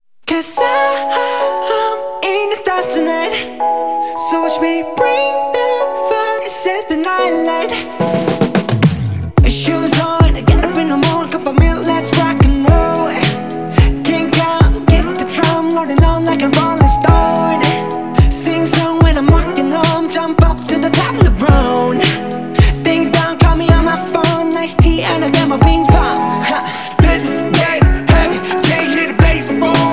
• K-Pop